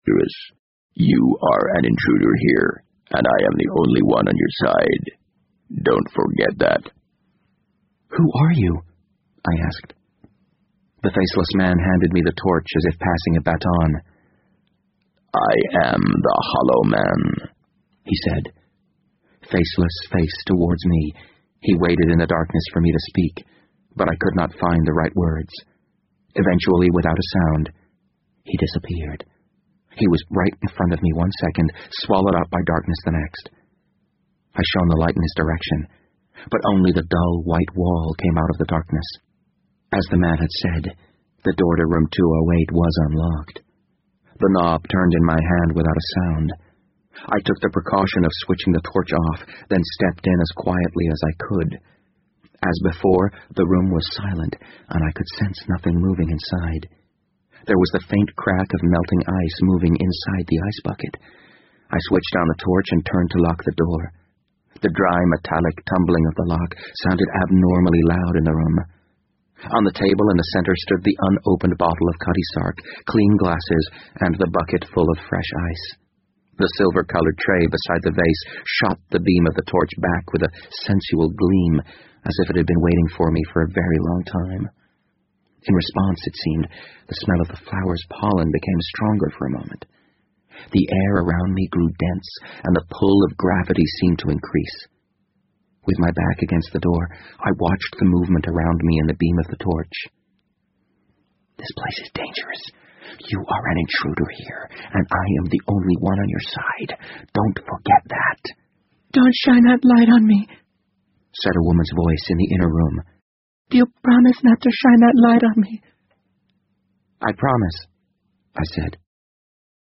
BBC英文广播剧在线听 The Wind Up Bird 015 - 4 听力文件下载—在线英语听力室